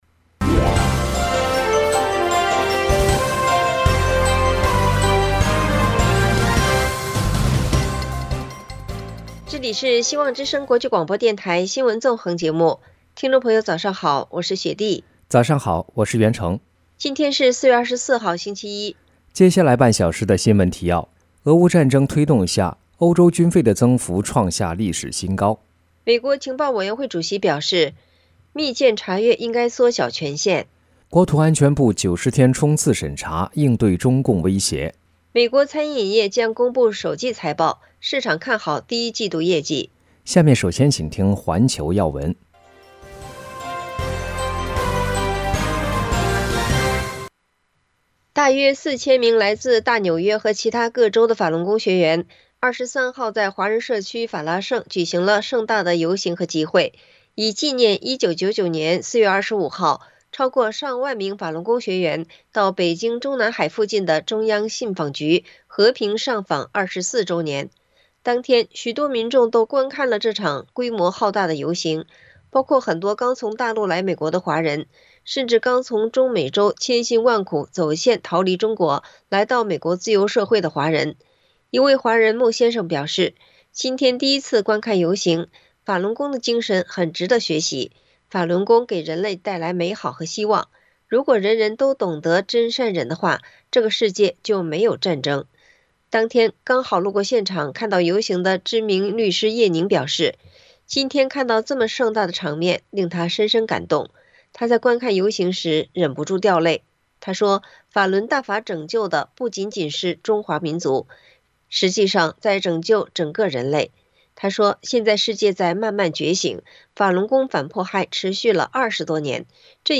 苏丹战事愈演愈烈 各国开始进行紧急撤侨任务【晨间新闻】